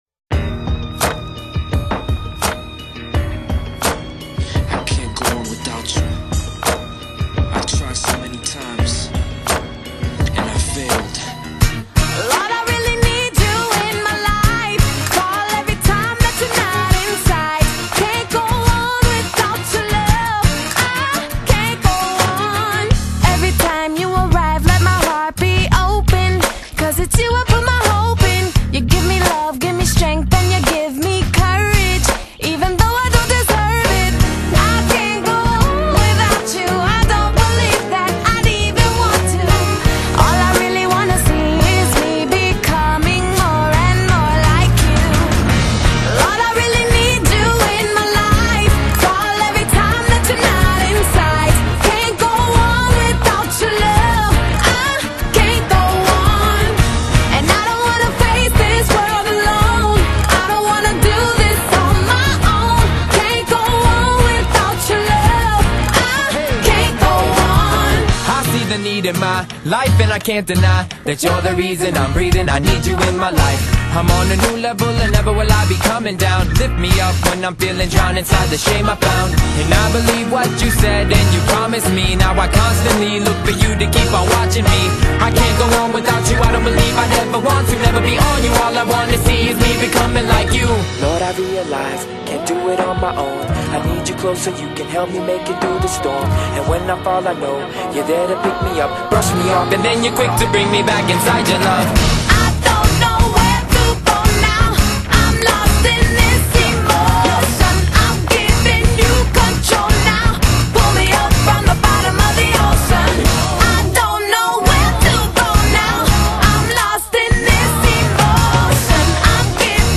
一首激昂的音乐
节奏感很不错